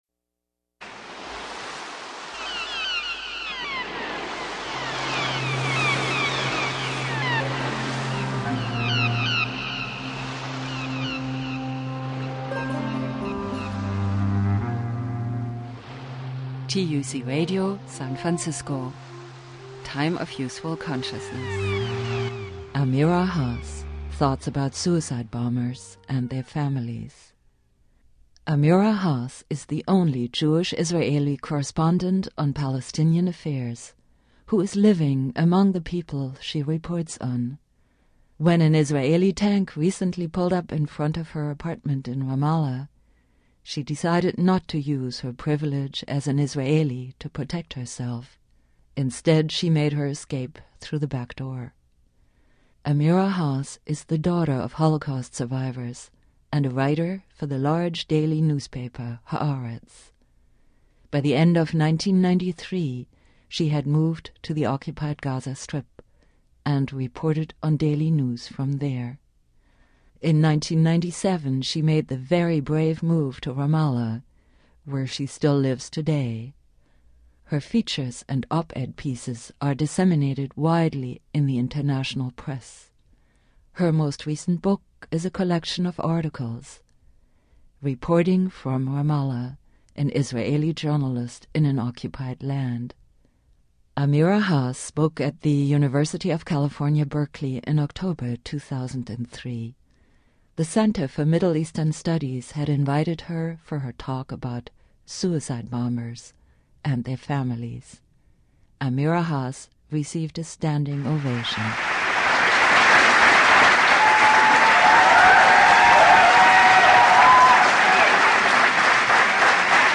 Amira Hass speaking at the University of California, Berkeley, in October 2003, on suicide bombers and their families.